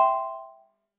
correct.wav